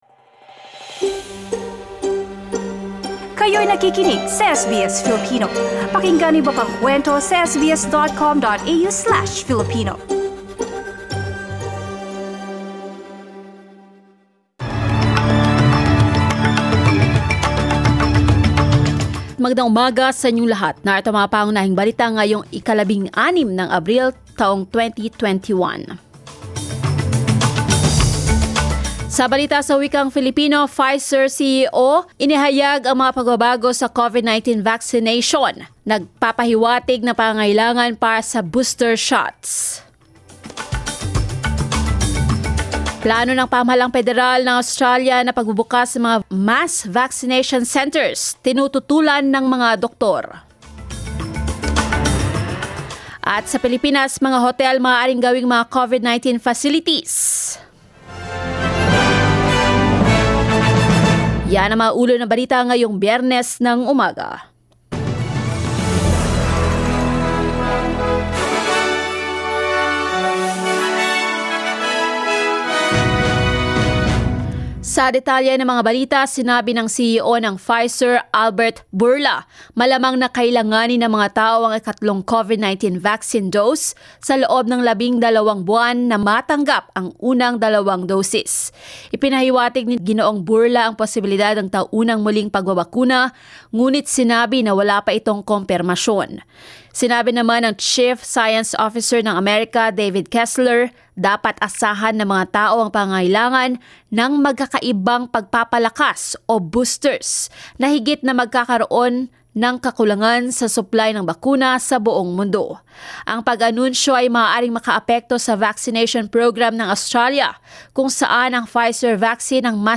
SBS News in Filipino, Friday 16 April